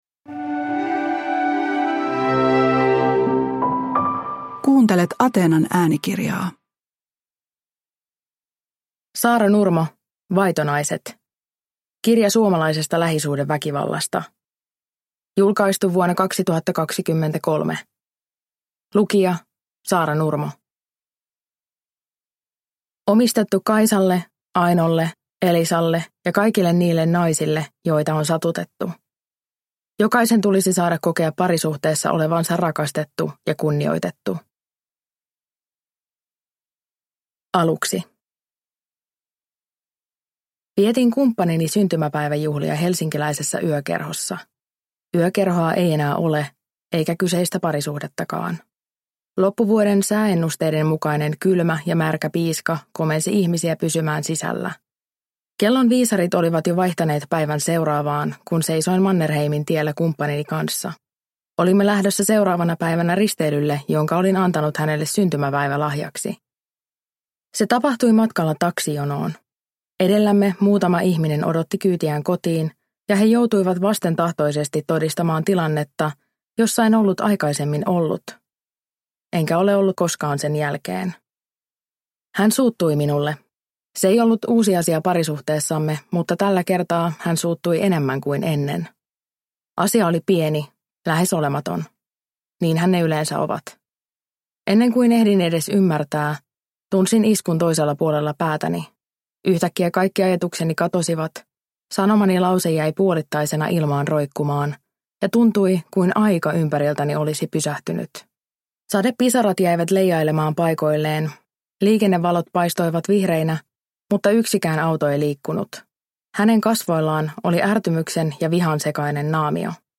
Vaitonaiset – Ljudbok – Laddas ner